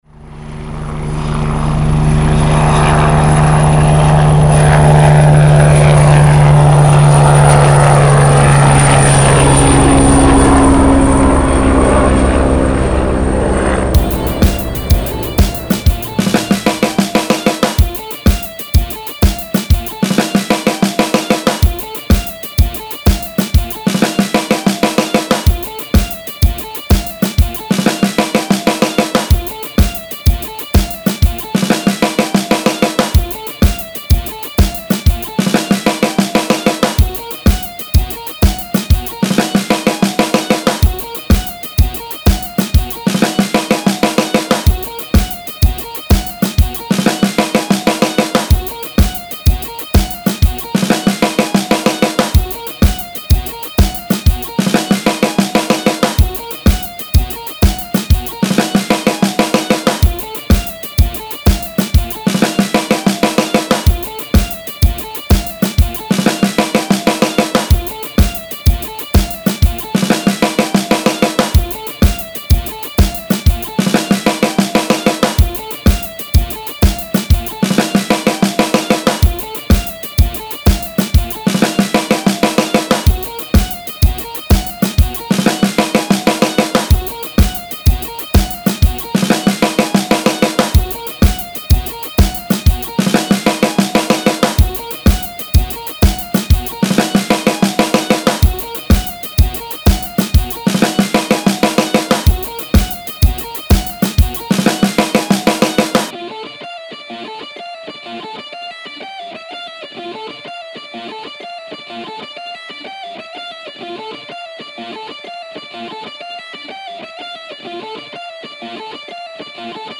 Royalty free w/ helicopter SFX